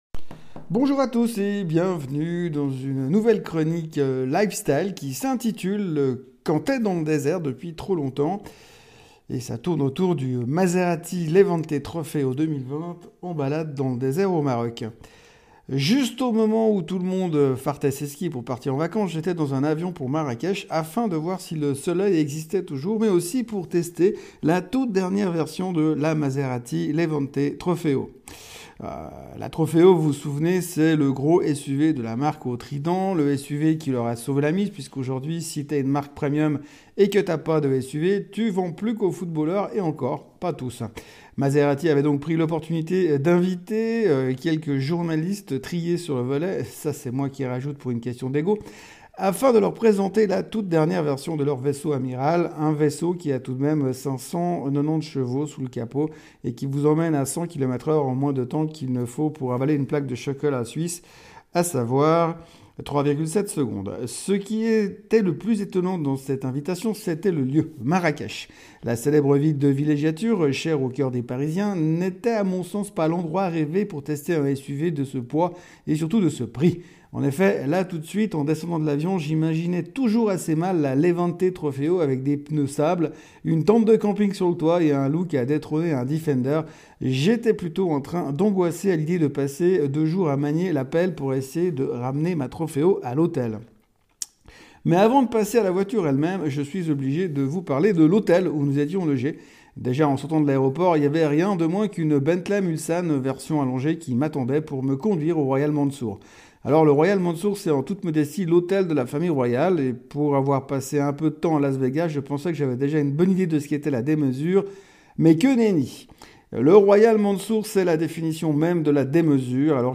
L’Audio de la Maserati (sans le bruit des échappements)